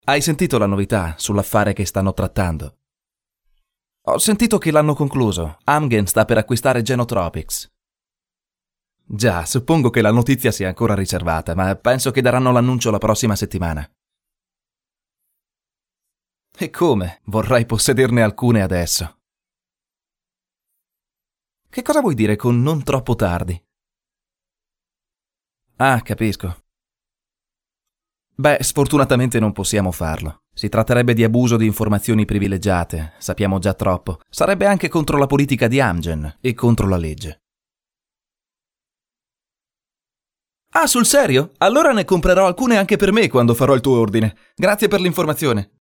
Sprechprobe: eLearning (Muttersprache):
Italian native voice over talent.